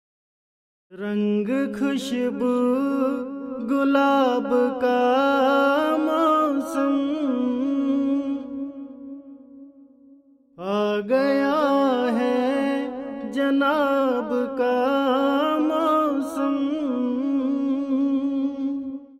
RNB钢琴100bpm Fmajor
Tag: 100 bpm RnB Loops Piano Loops 3.23 MB wav Key : F